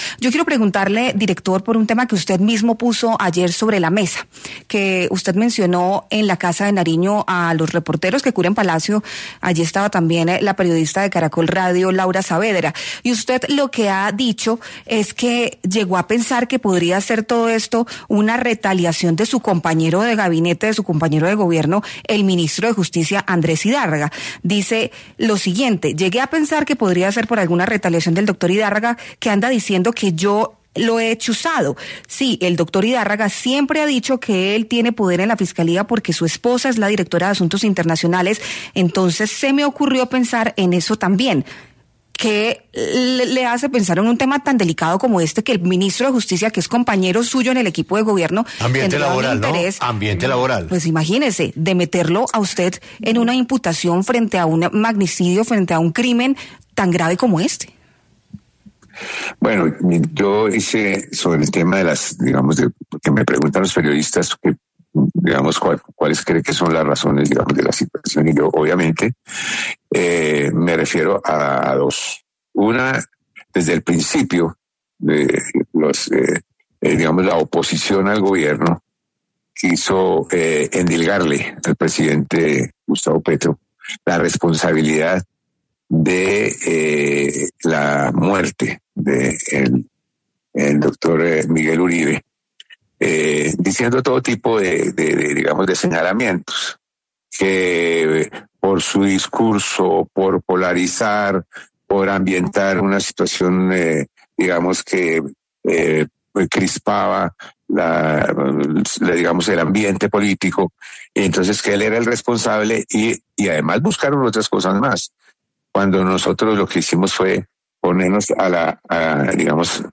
El director de la Unidad Nacional de Protección (UNP), Augusto Rodríguez, habló en los micrófonos de 6AM W en donde profundizó sobre sus señalamientos al ministro de Justicia, Andrés Idárraga, luego de que comentó que el jefe de esa cartera podría estar siendo parte de que se busque una imputación en su contra en el caso de Miguel Uribe.